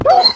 1.21.4 / assets / minecraft / sounds / mob / wolf / hurt1.ogg
hurt1.ogg